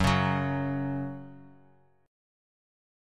Listen to F#5 strummed